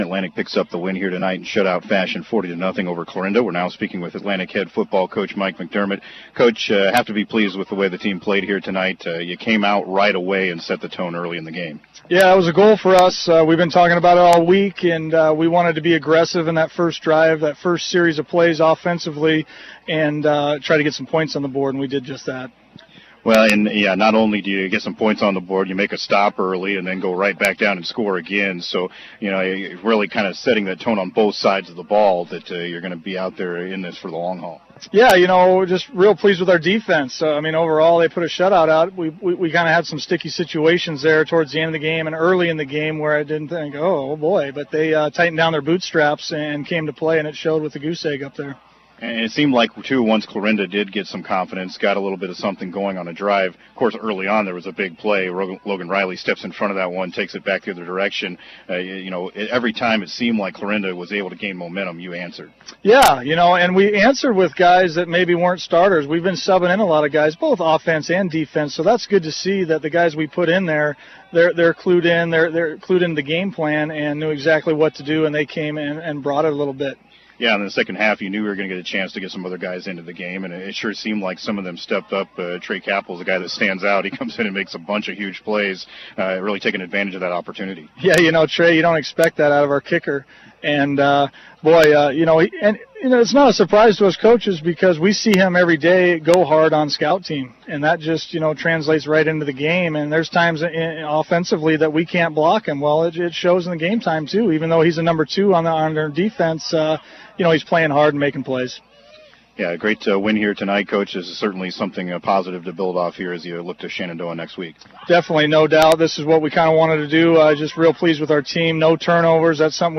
(Podcast) KJAN Morning Sports report, 12/01/2017
The 7:20-a.m. Sportscast